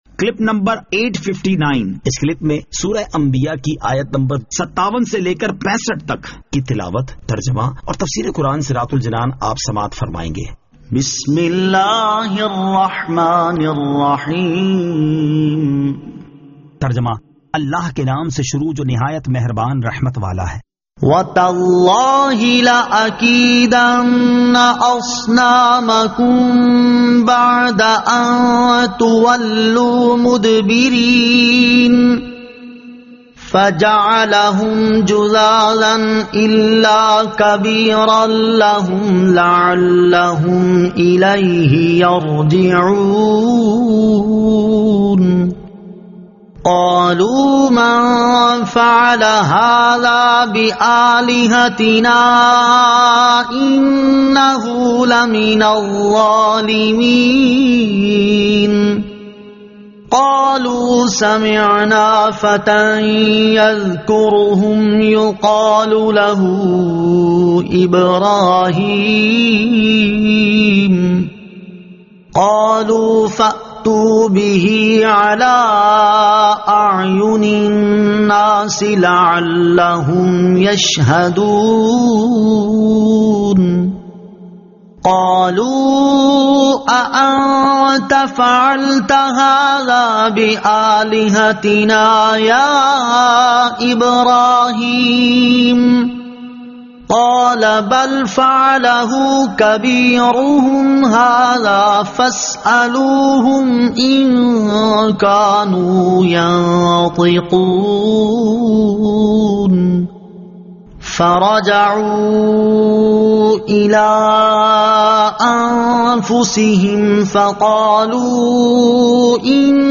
Surah Al-Anbiya 57 To 65 Tilawat , Tarjama , Tafseer